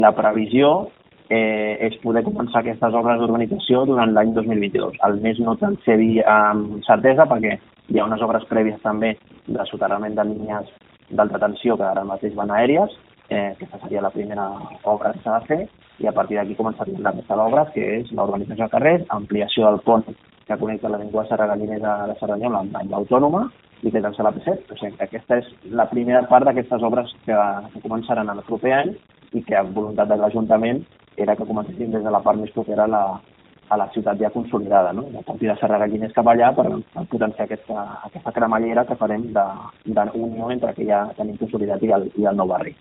Declaracions de Carlos Cordon